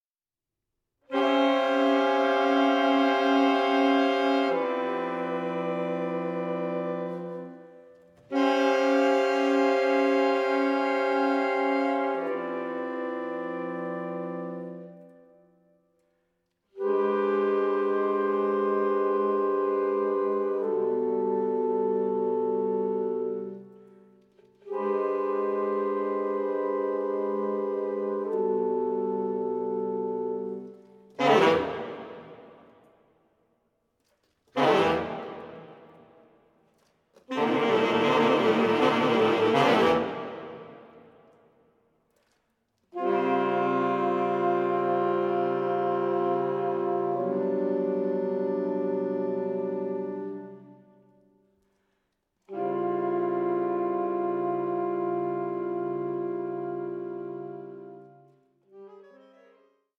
A SUBLIME FUSION OF JAZZ MASTERY AND ORCHESTRAL ELEGANCE
Recorded live